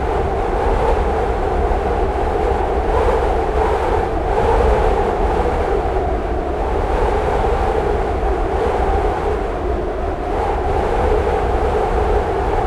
KHLOUpperWind01.wav